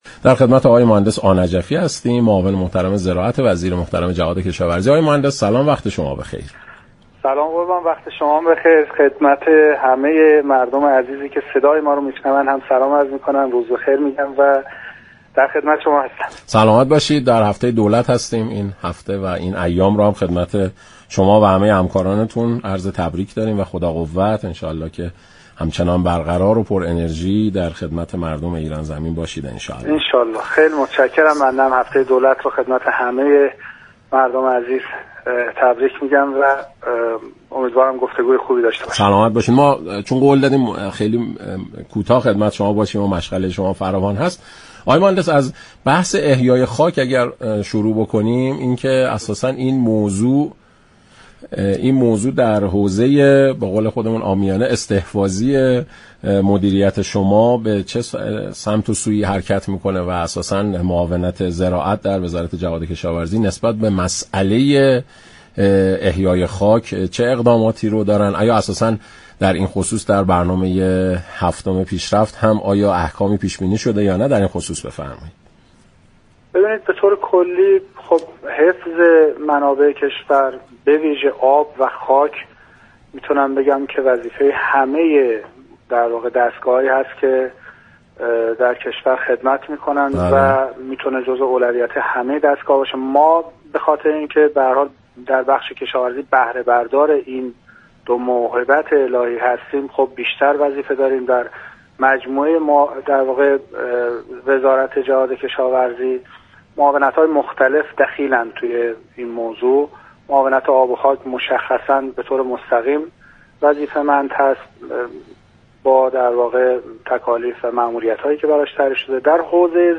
معاون زراعت وزیر‌جهاد‌كشاورزی در برنامه ایران امروز گفت: خشكسالی‌های كشور كه در 50 سال اخیر بی‌سابقه بوده منابع آبی را با بحران جدی روبرو كرده است.